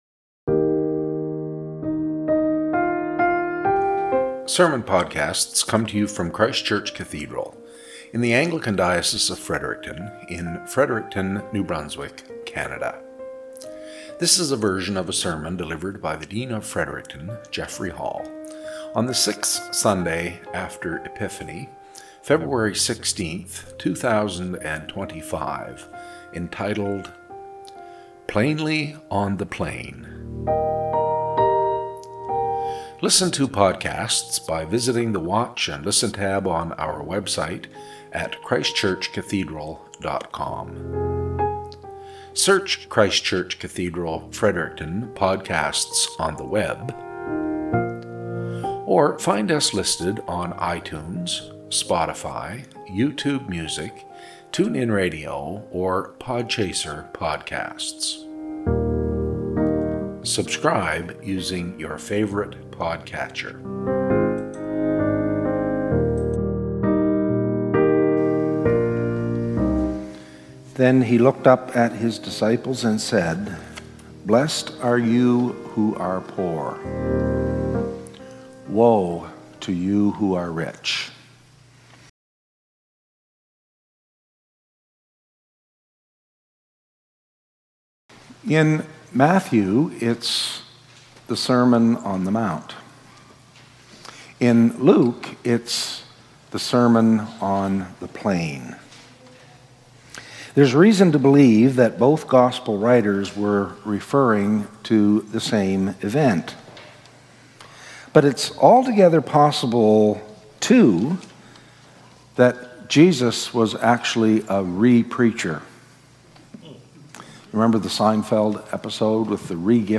Cathedral Podcast - SERMON -
Podcast from Christ Church Cathedral Fredericton
Lections for the Sixth Sunday after Epiphany